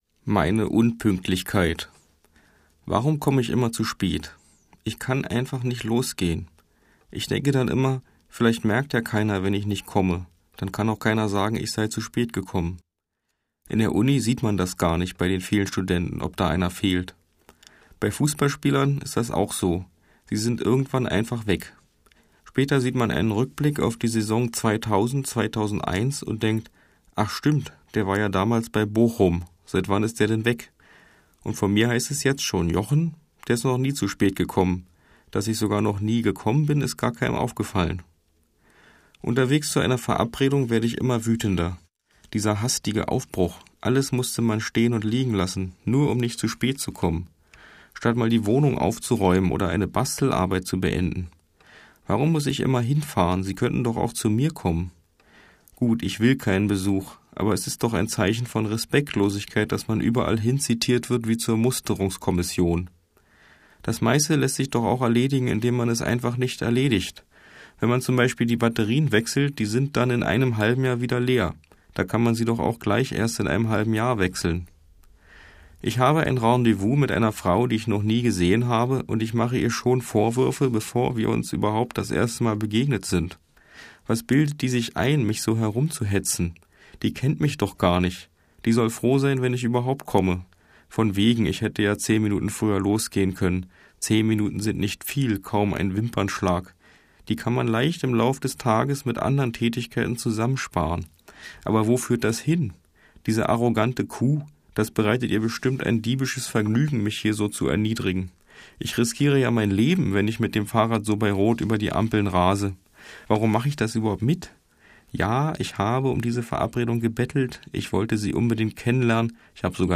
der seine Texte mit unnachahmlicher Stoik vorträgt